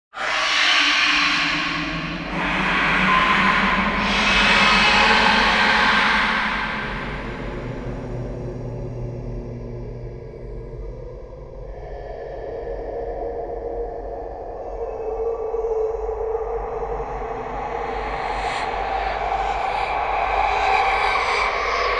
На этой странице собраны пугающие звуки ада: от далеких воплей до навязчивого скрежета.
Звук потустороннего перехода грешников из бездны, где живут мертвецы